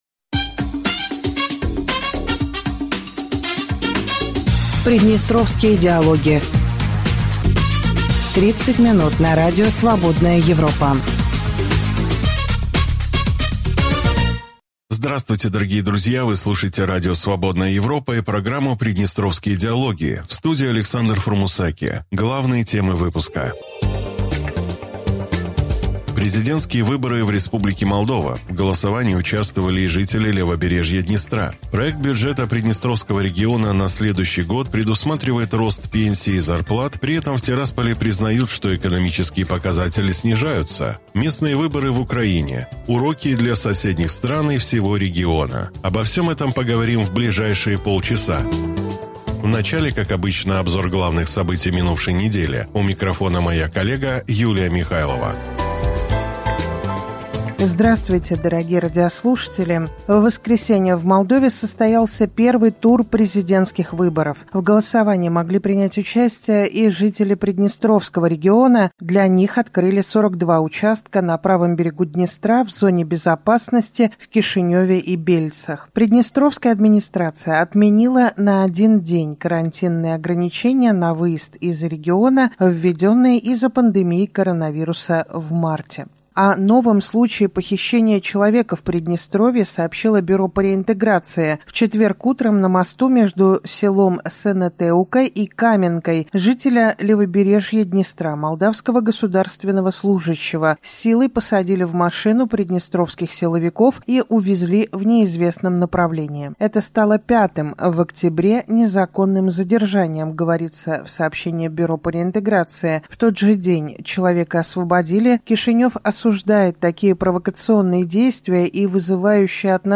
Вы слушаете радио Свободная Европа и программу Приднестровские диалоги.